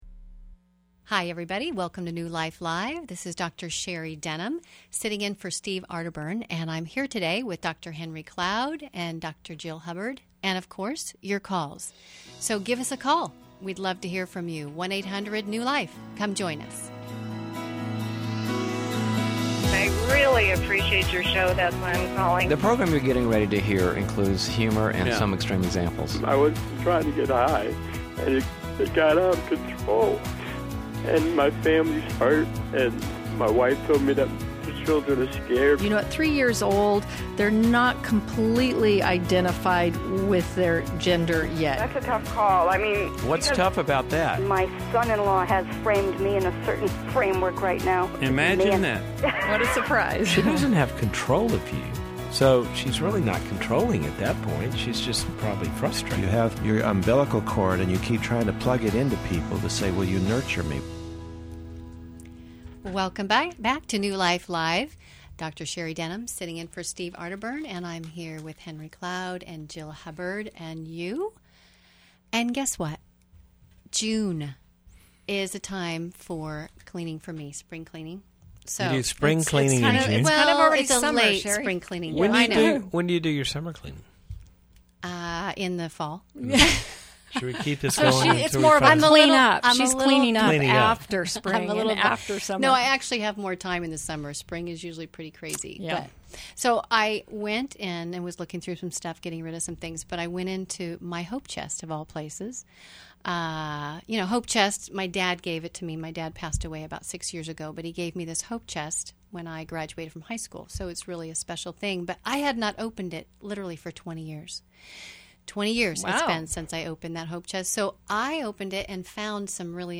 Caller Questions: 1. Should my girlfriend have friends of the opposite sex? 2.